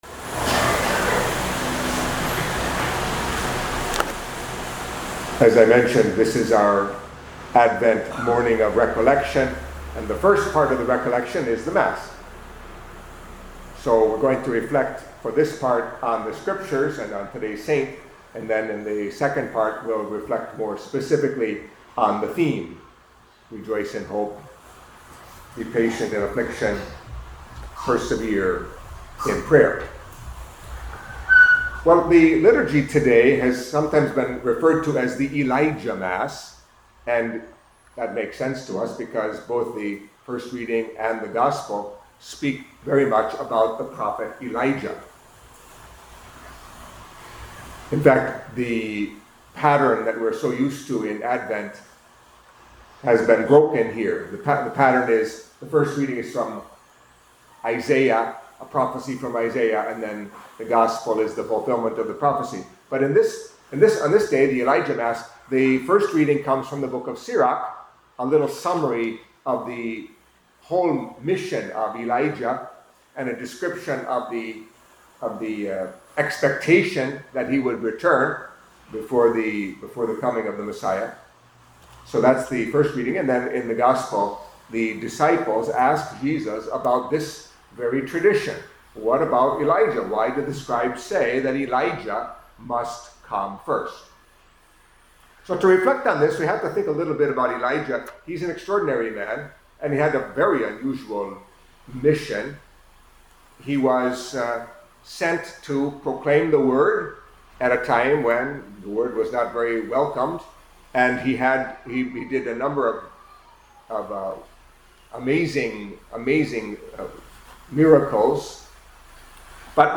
Anawim Homilies